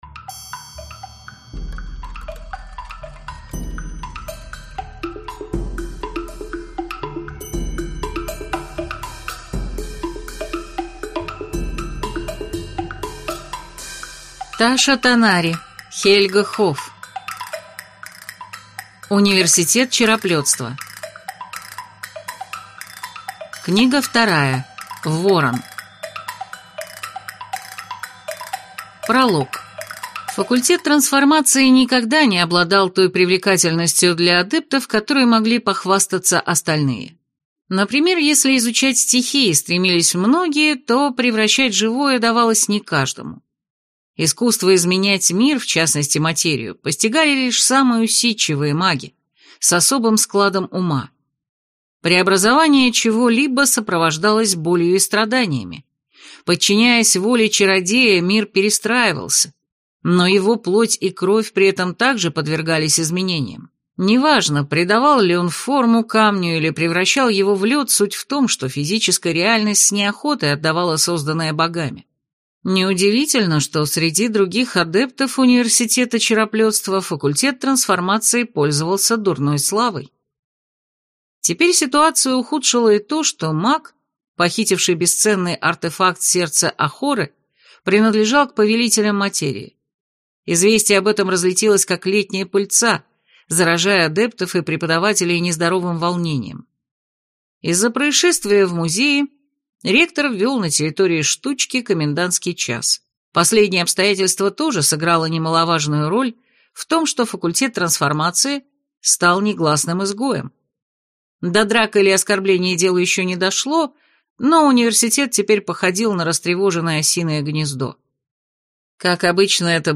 Аудиокнига Ворон | Библиотека аудиокниг